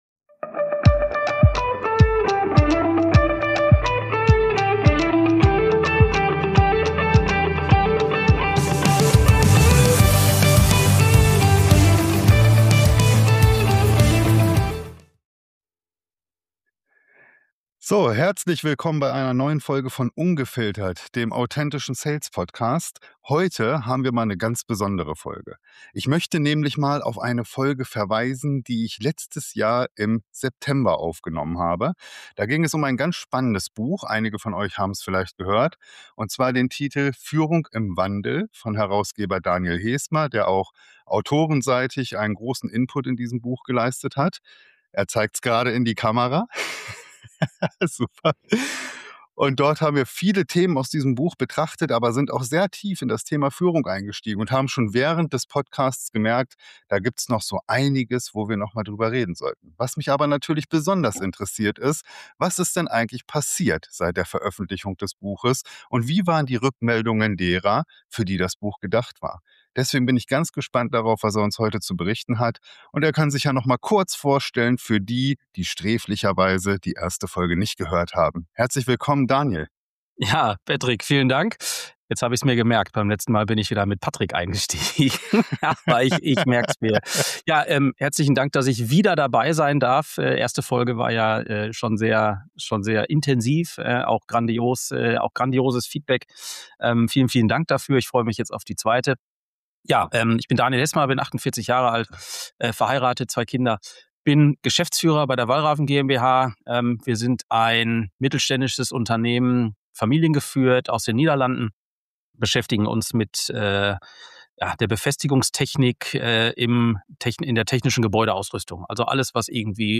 Ein tiefgehendes, praxisnahes Gespräch für alle, die führen, führen wollen oder verstehen möchten, warum moderne Führung heute herausfordernder – aber auch relevanter – ist denn je.